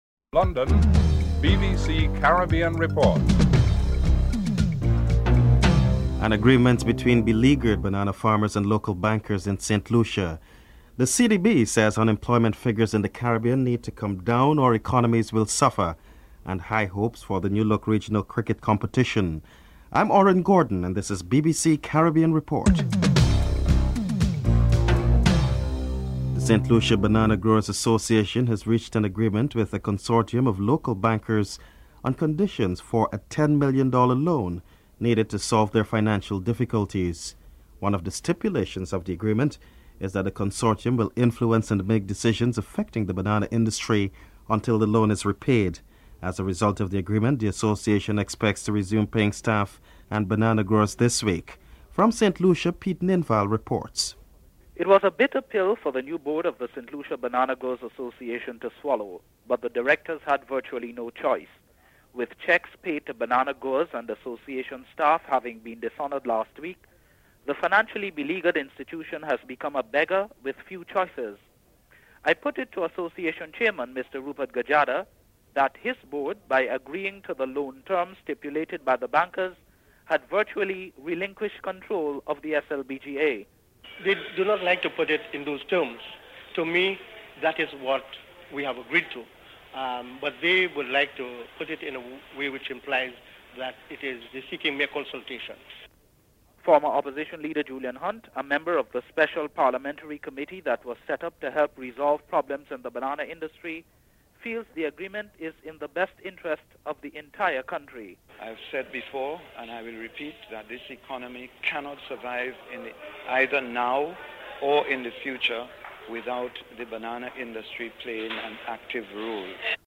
1. Headlines (00:00-00:26)
6. The British government and its dependent territories in the Caribbean. Hubert Hughes, Anguilla's Chief Minister is interviewed.